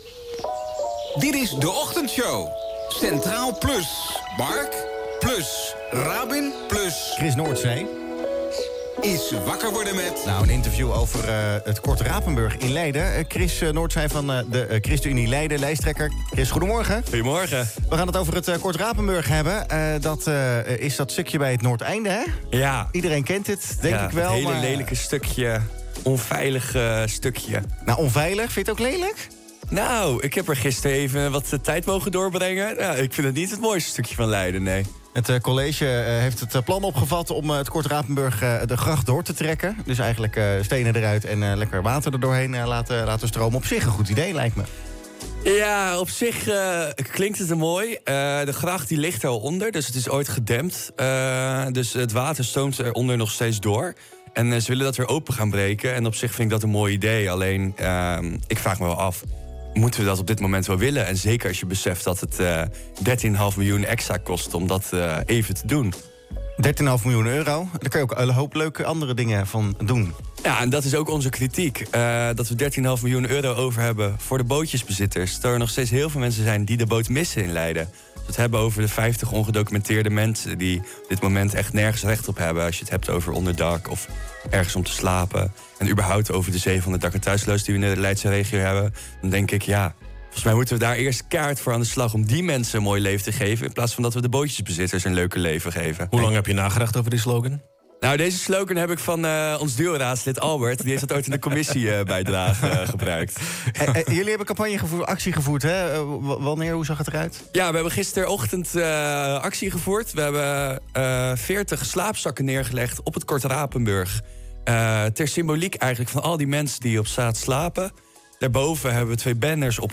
vertelt over de actie in de Centraal + Ochtendshow: